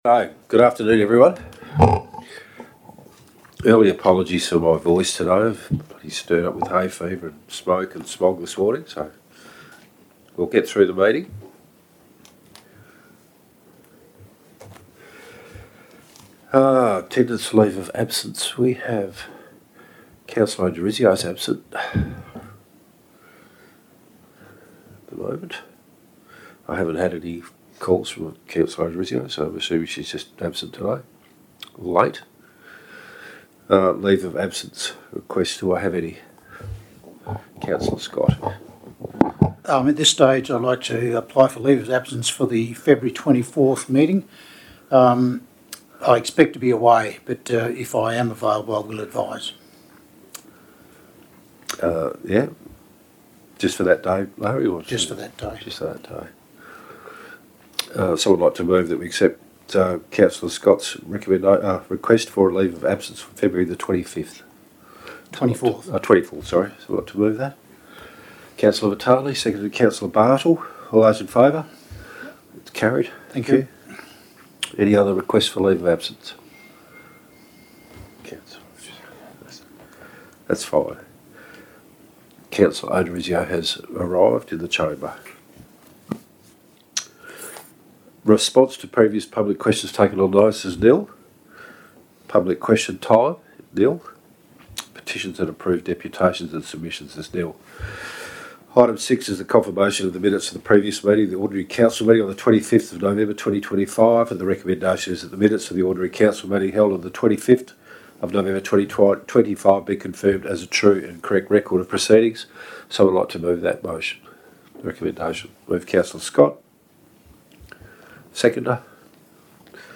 December Ordinary Council Meeting » Shire of Waroona